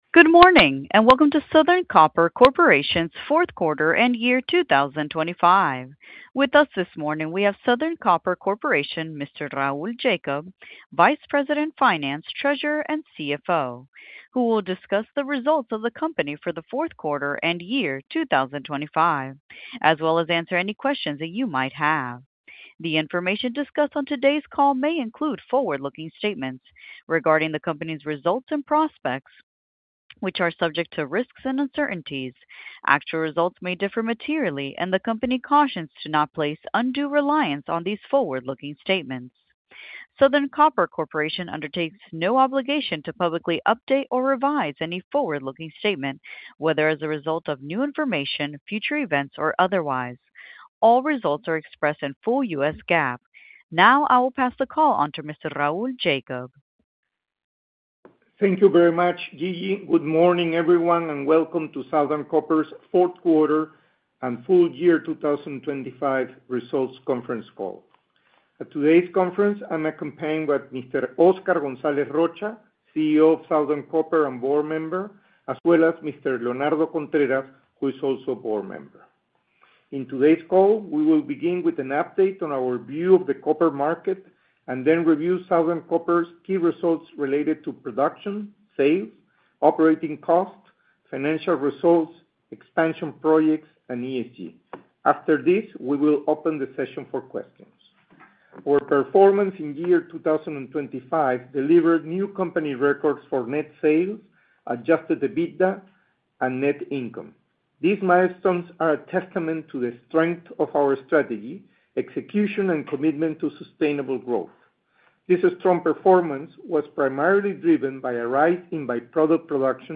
Southern Copper Corporation 4Q-2025 conference call replay